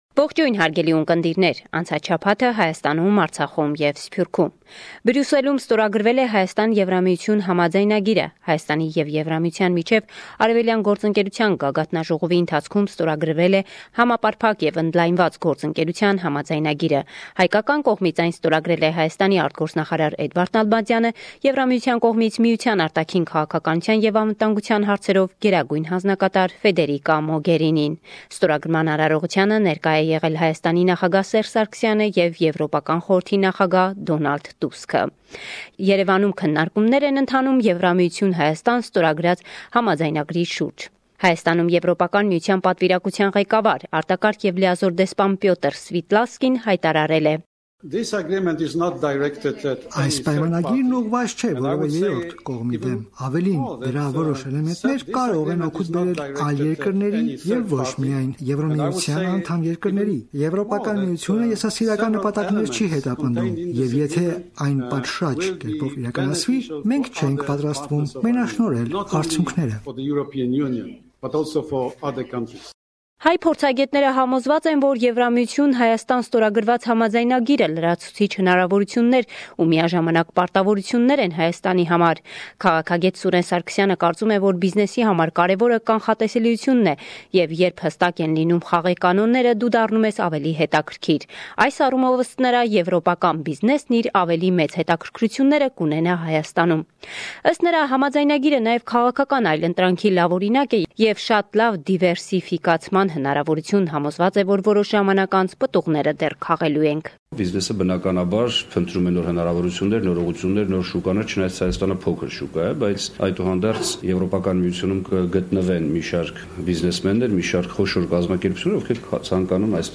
Վերջին Լուրերը – 28 Նոյեմբեր, 2017